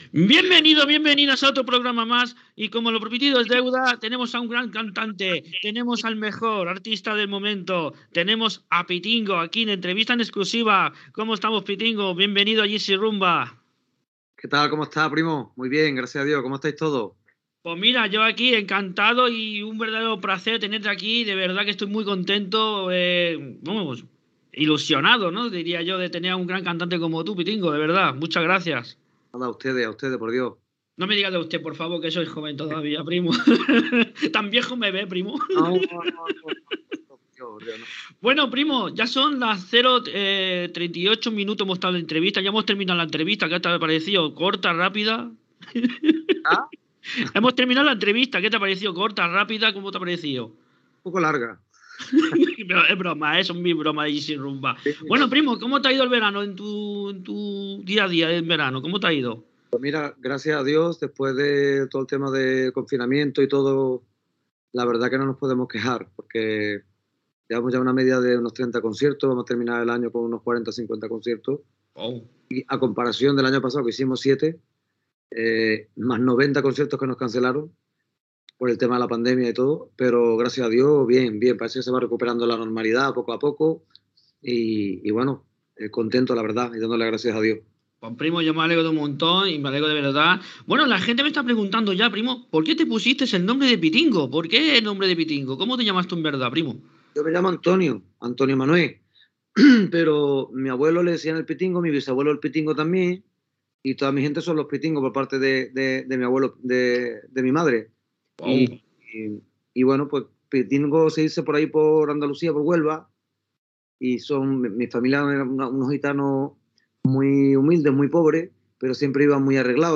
Entrevista al cantant Pitingo (Antonio Manuel Álvarez ) que està a Mallorca per participar al I Festival Aspanob Mallorca